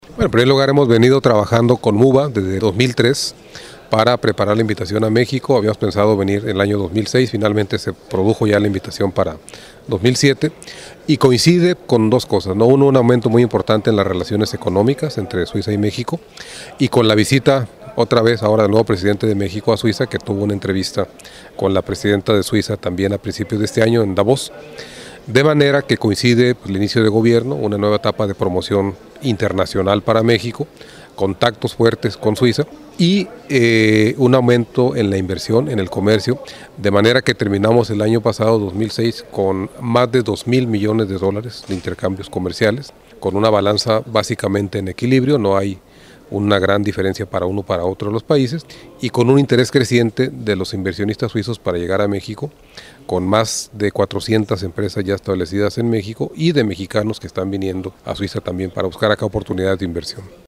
El embajador de México ante Berna, José Luis Bernal Rodríguez, habla a swissinfo sobre la presencia de su país en la muba, la mayor feria comercial de Suiza y que cada año tiene lugar en Basilea.